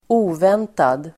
Uttal: [²'o:ven:tad]